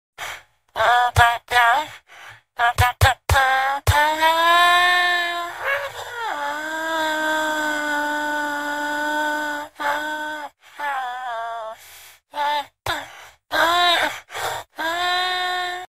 Etiquetas: meme, soundboard